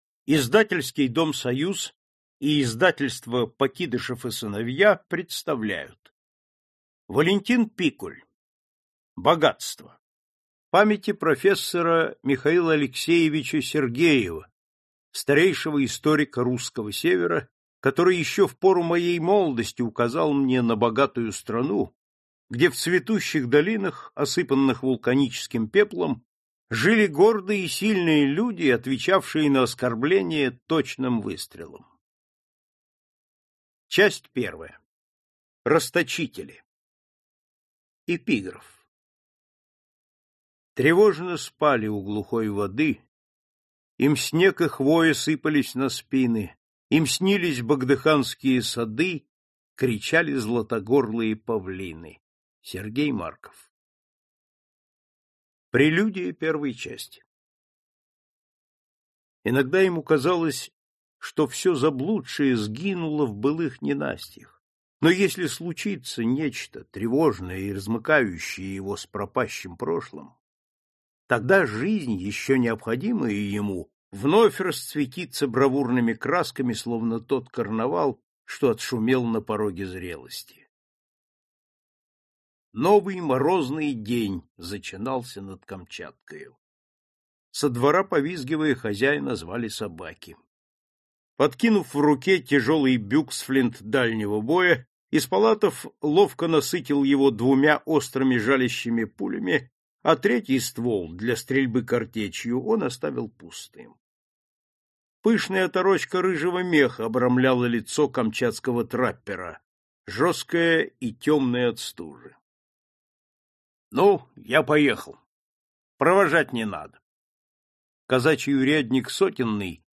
Аудиокнига Богатство. Часть I «Расточители» | Библиотека аудиокниг